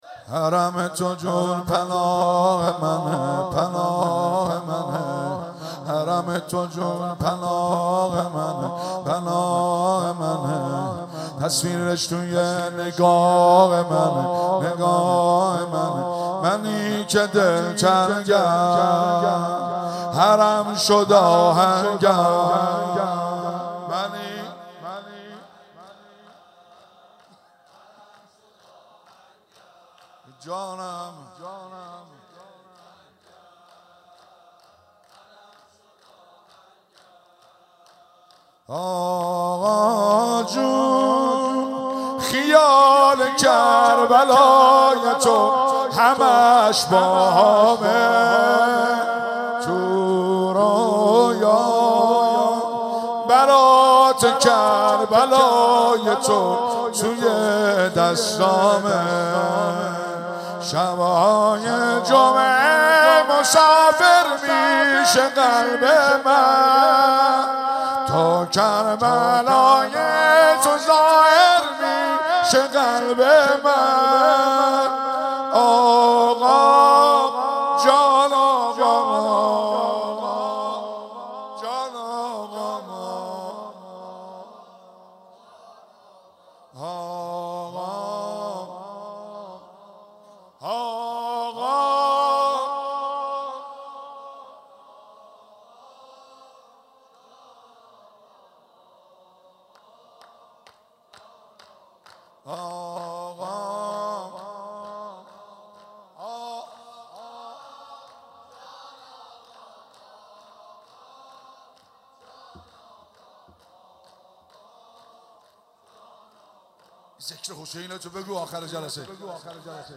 مراسم مناجات خوانی شب هفدهم ماه رمضان 1444
مناجات خوانی: